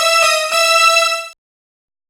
Synth Lick 49-04.wav